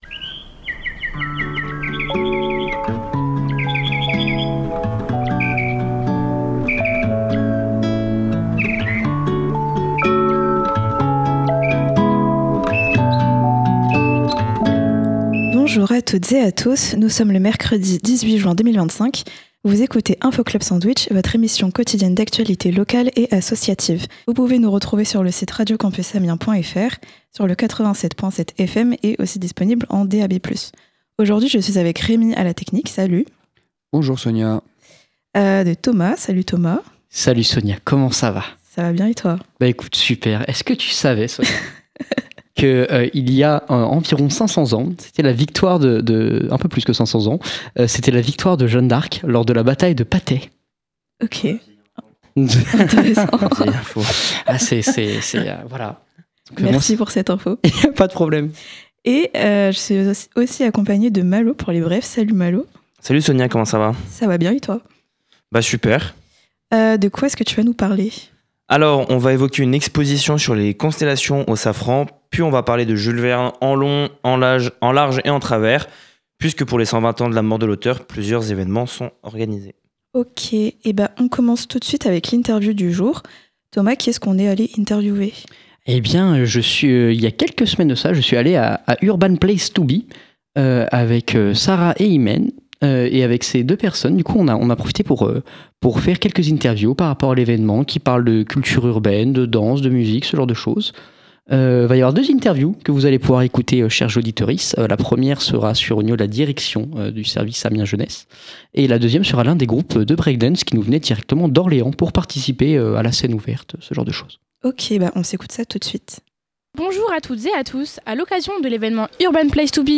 Aujourd’hui, on écoute deux interviews réalisées pendant l’évènement Urban Place To Be.